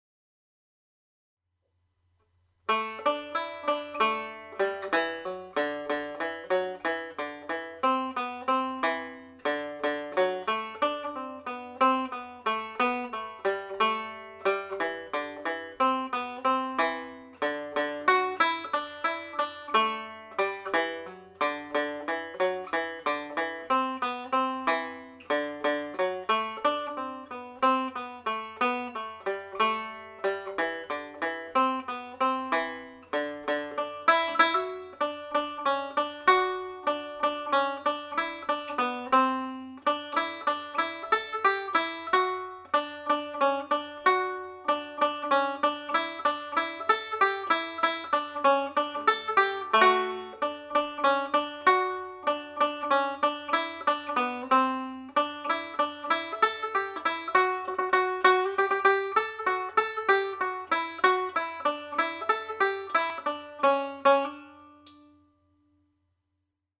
Pure Banjo » Advanced Level
The-Pipe-on-the-Hob-slow.mp3